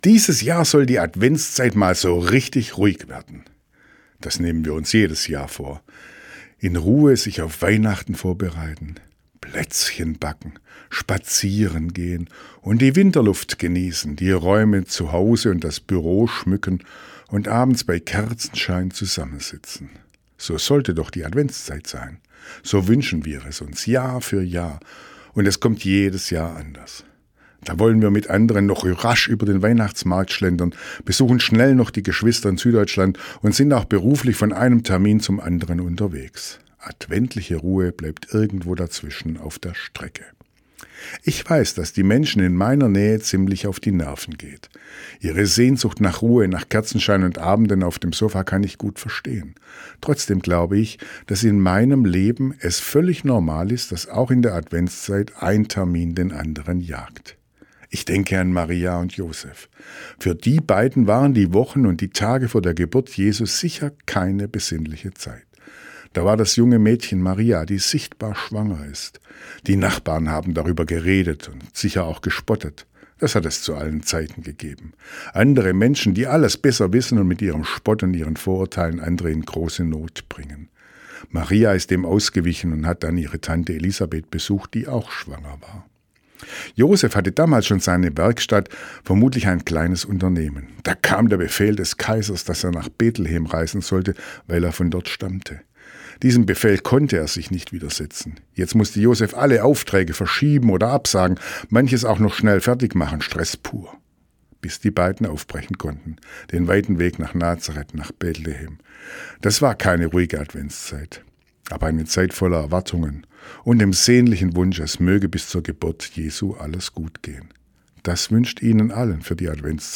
Radioandacht vom 5. Dezember